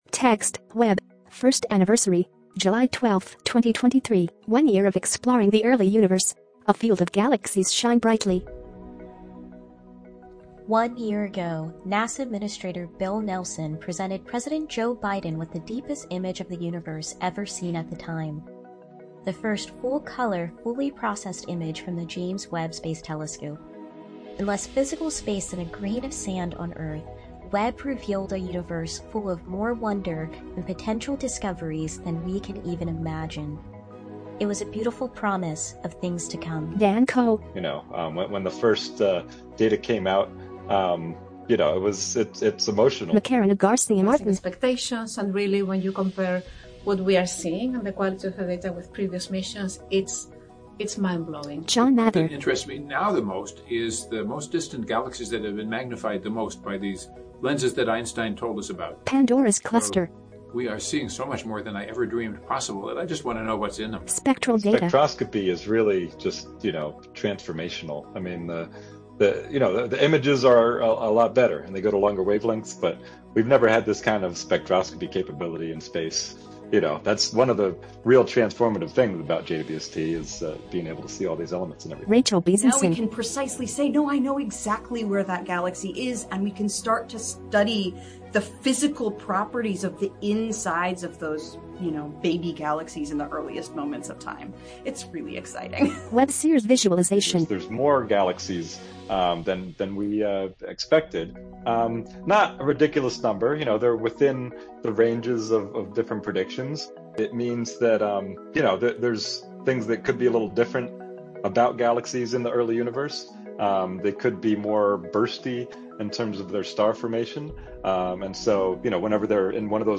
• Audio Description
Audio Description.mp3